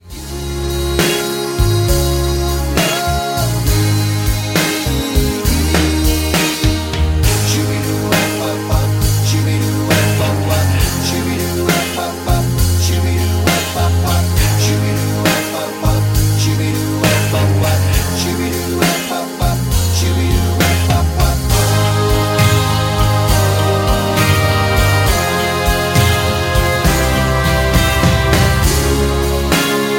G#
MPEG 1 Layer 3 (Stereo)
Backing track Karaoke
Pop, 2000s